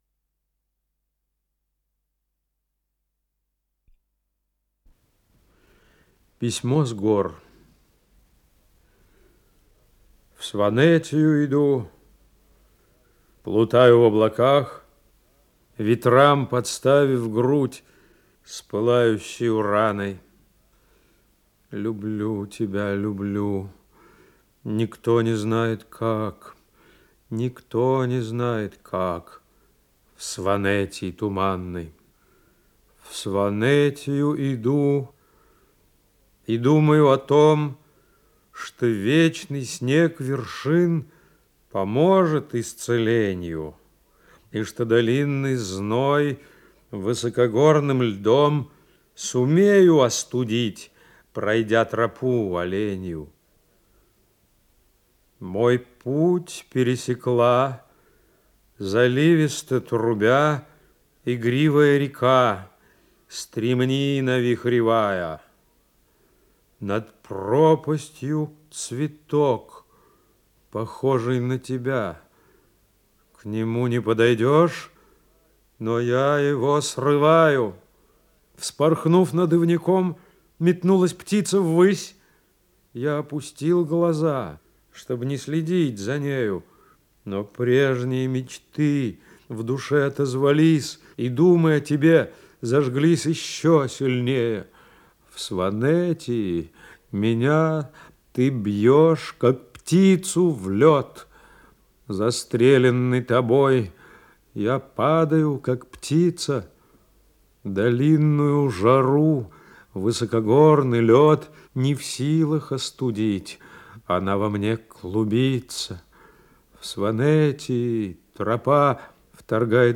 Исполнитель: Владимир Рецептер, Яков Смоленский - чтение
Стихи